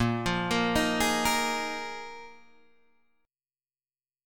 A#7sus4 chord